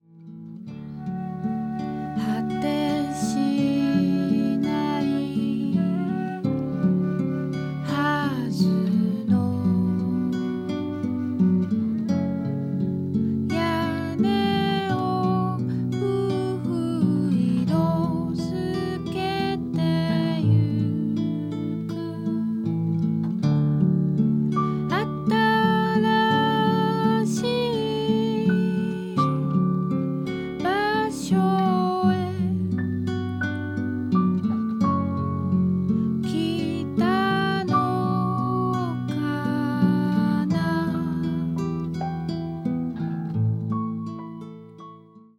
儚くも豊かなメロディーを持つ楽曲
彼らのアングラ的な部分よりも、素朴な中に高い音楽性（と中毒性）が見える、幽玄でメロディアスな好選曲の内容となっています。